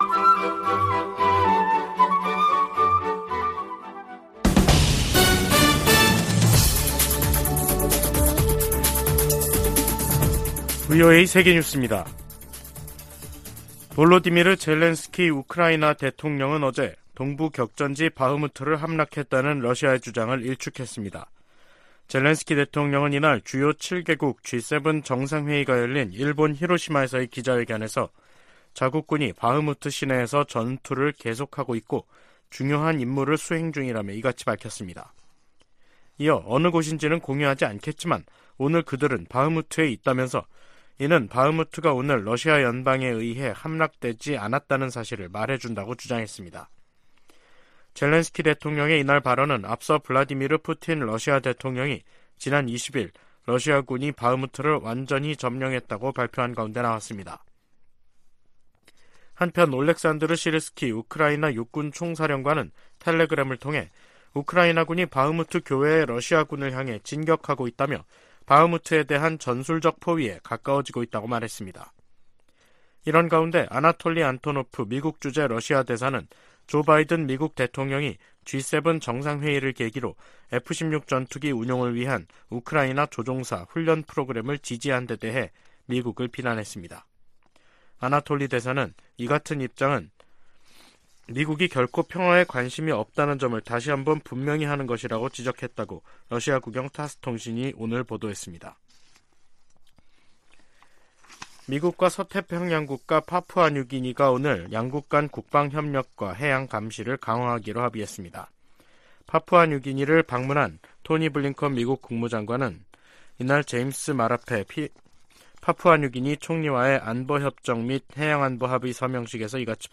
VOA 한국어 간판 뉴스 프로그램 '뉴스 투데이', 2023년 5월 22일 2부 방송입니다. 조 바이든 미국 대통령과 윤석열 한국 대통령, 기시다 후미오 일본 총리가 히로시마 회담에서 새로운 공조에 합의했습니다. 주요7개국(G7) 정상들은 히로시마 공동성명에서 북한의 계속되는 핵과 탄도미사일 위협을 규탄했습니다. 미일 외교장관들이 북한의 완전한 비핵화를 위한 미한일 3각 공조의 중요성을 강조했습니다.